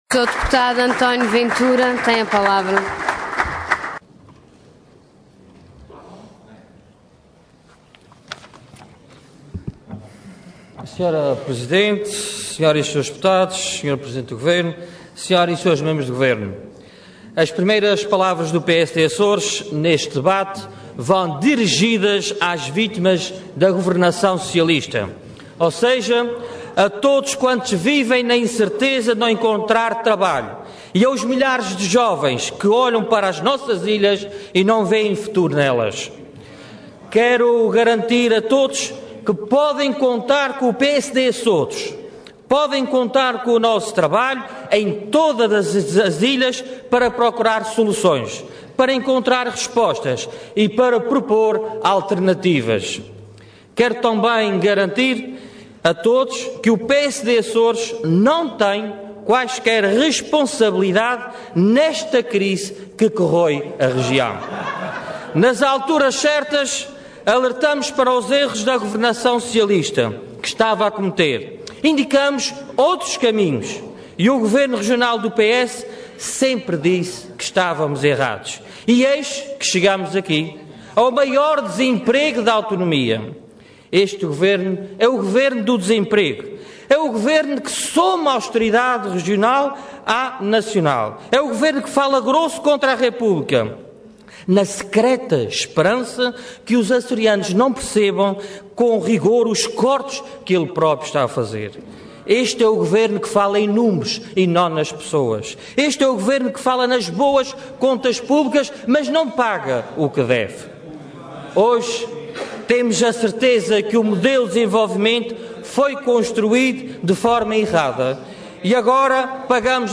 Intervenção Intervenção de Tribuna Orador António Ventura Cargo Deputado Entidade PSD